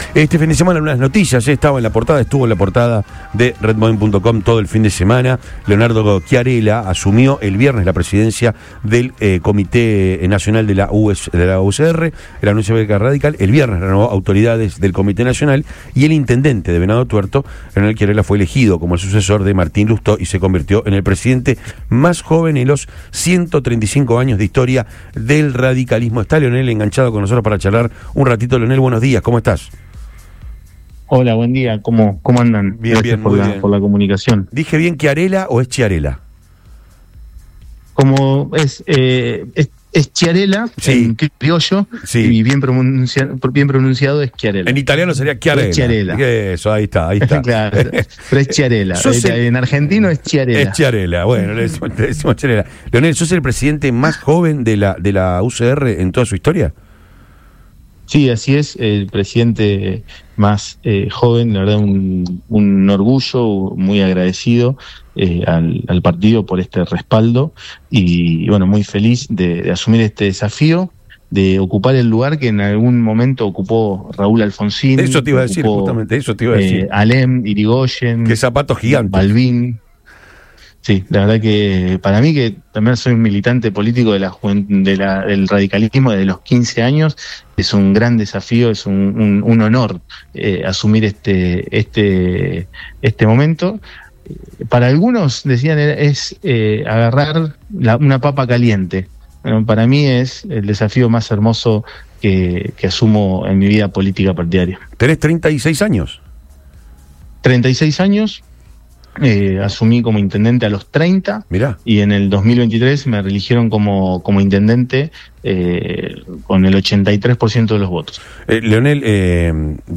En diálogo con Antes de Todo de Radio Boing, el flamante titular partidario se mostró orgulloso y consciente del peso histórico de su nuevo cargo, ocupando un lugar que en su momento tuvieron figuras como Raúl Alfonsín, Leandro Alem e Hipólito Yrigoyen.